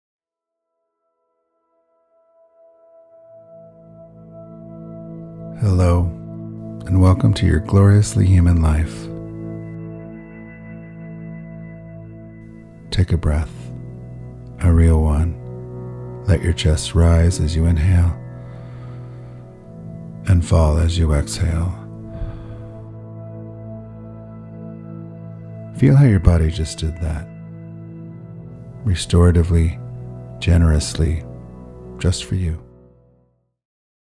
I want to be honest about what it is and what it isn't. It isn't a guided meditation with some breathing.
The meditation starts with a breath.